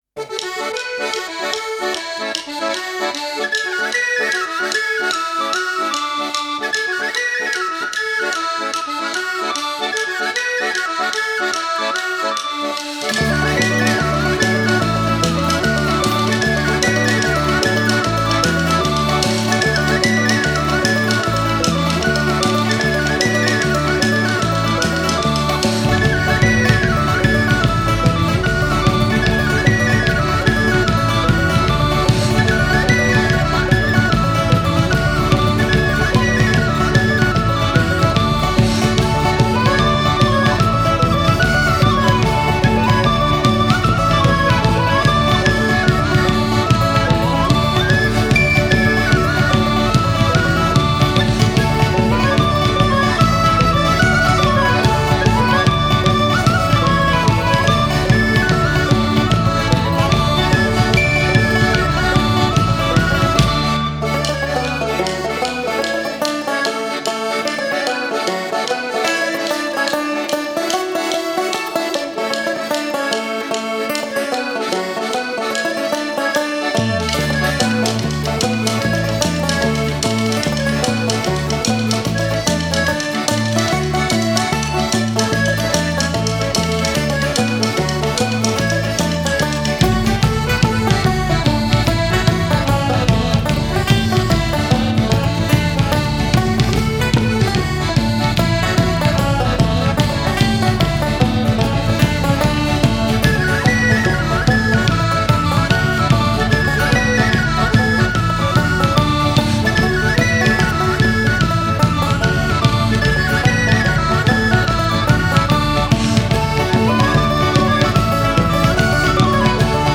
BBC Live 1984-89